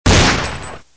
disparo
Sonido FX 17 de 42
disparo.mp3